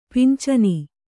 ♪ pincani